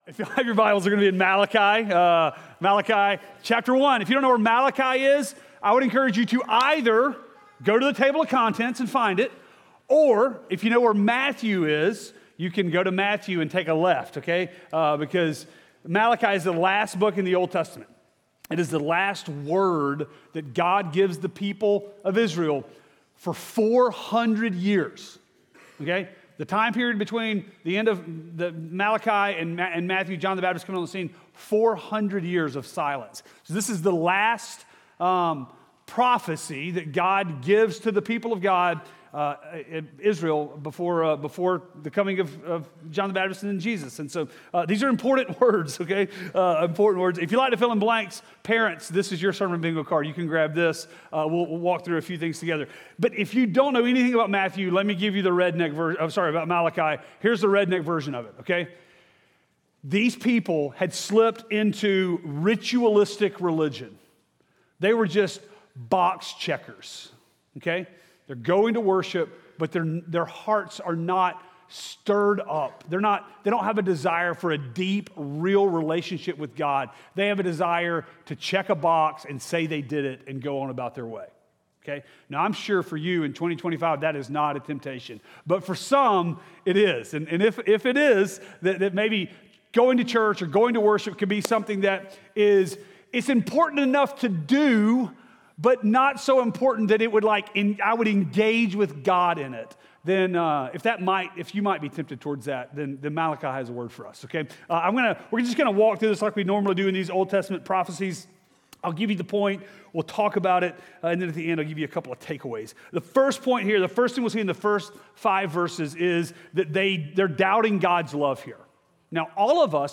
Sermons Malachi Despising The God That Loves Us!